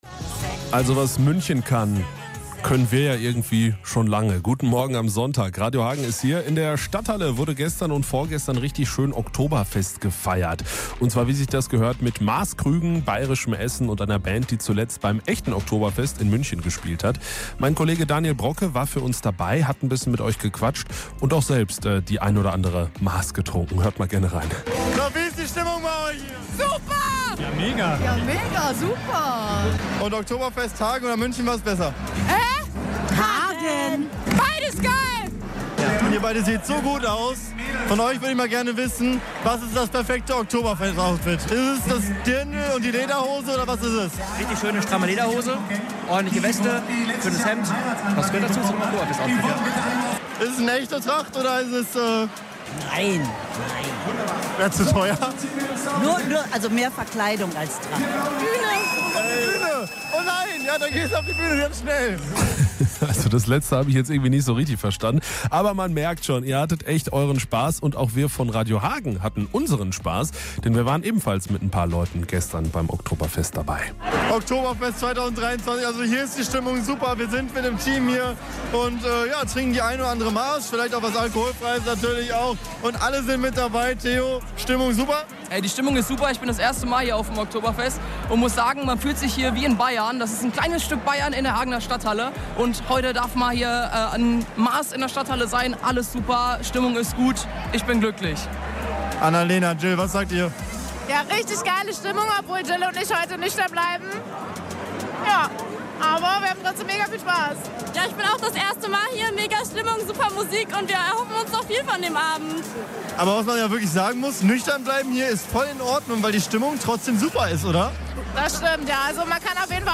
Hunderte Hagener haben am Wochenende beim Oktoberfest in der Stadthalle gefeiert. Hier gibt es alle Eindrücke und unseren Programm-Mitschntt.